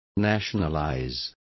Complete with pronunciation of the translation of nationalize.